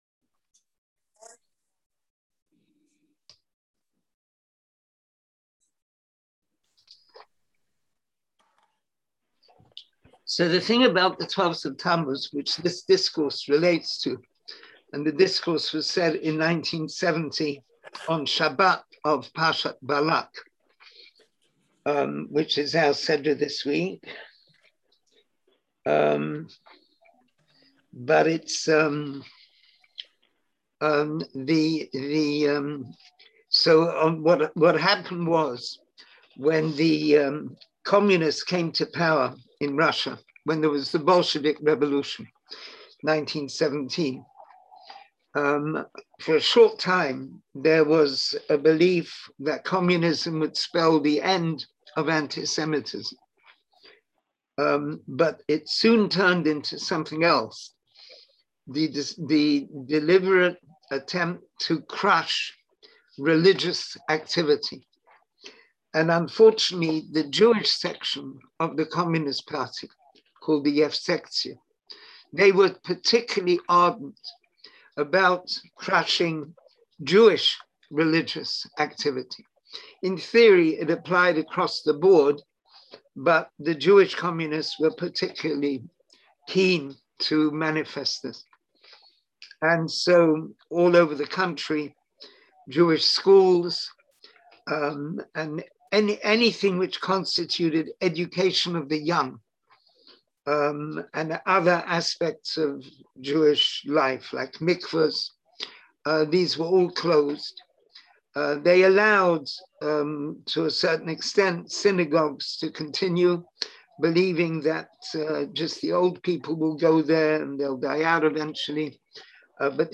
Class audio Listen to the class Class material Download the related text Join the class?